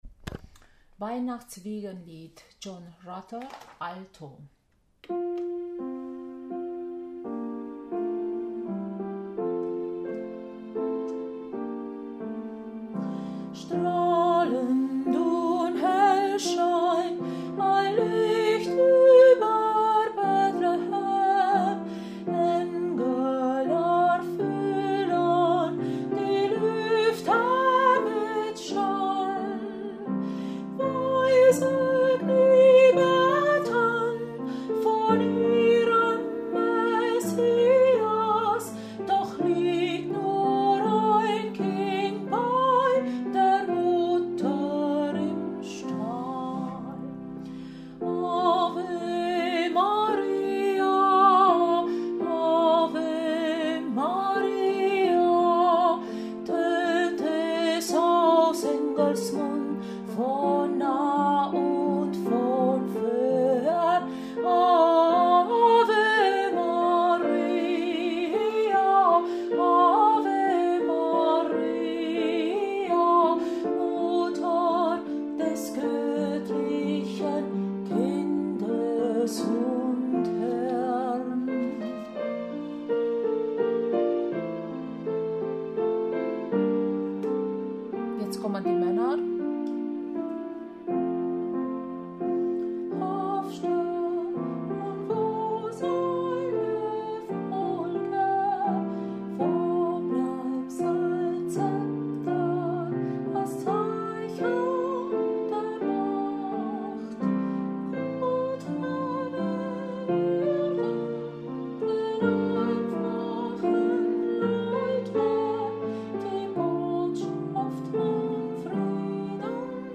John Rutter Weihn.-Wiegenlied Alto